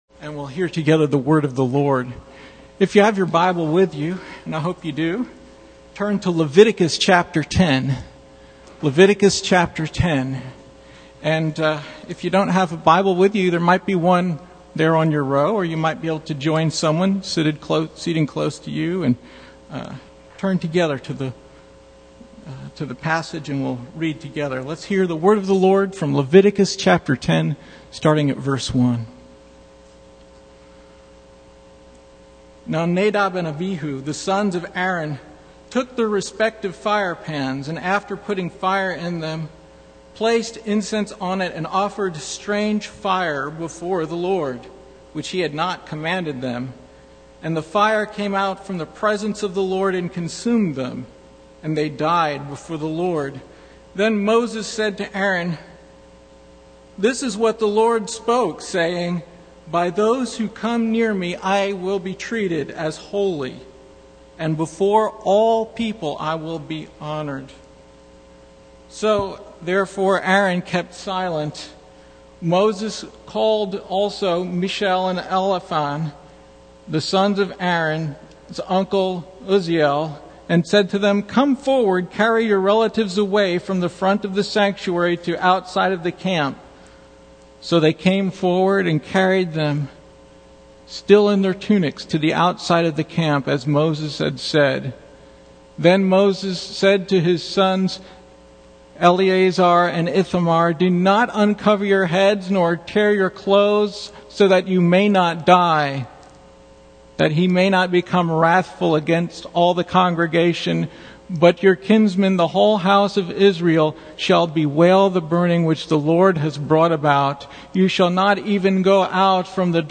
Passage: Leviticus 10:1-20 Service Type: Sunday Morning